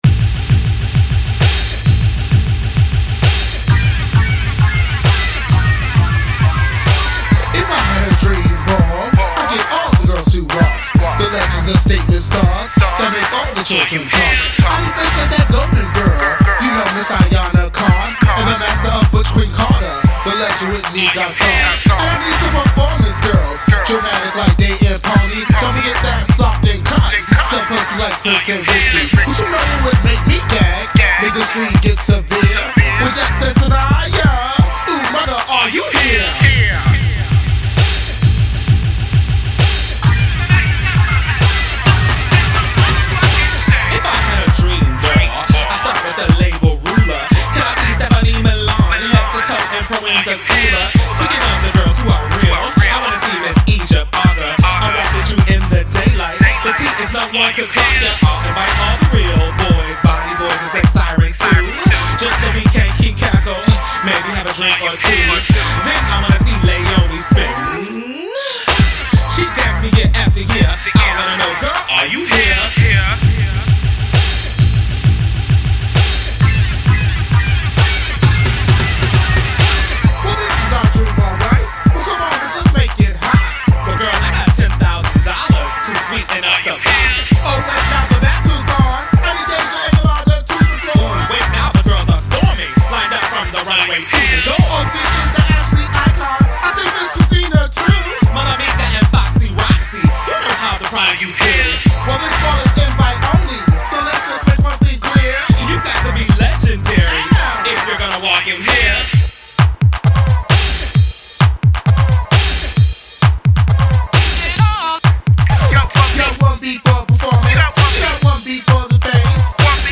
ballroom vogue beats